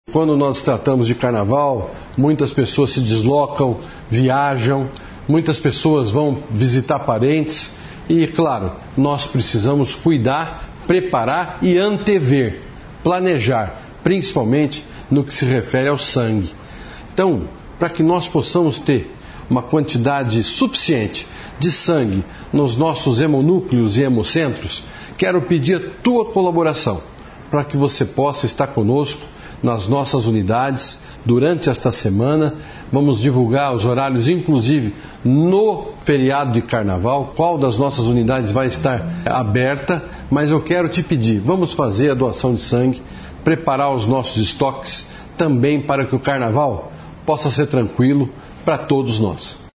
O secretário da Saúde, Beto Preto, ressalta que o número de traumas aumenta durante os feriados e por isso as doações são fundamentais.